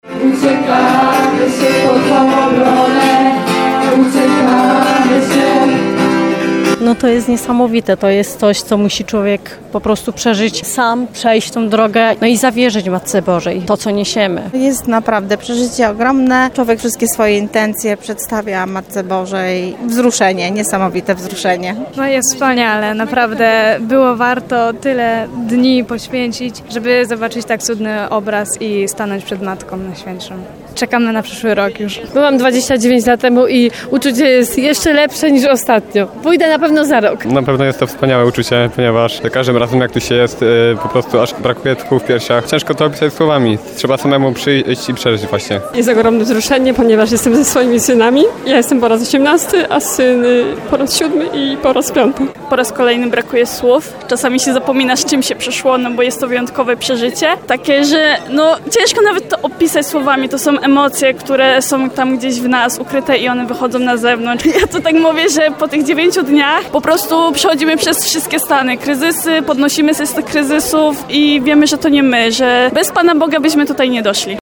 Jako pierwsi przed ikoną Pani Jasnogórskiej stanęli pielgrzymi z grupy 14.
pielgrzymi-14-z-muzyka.mp3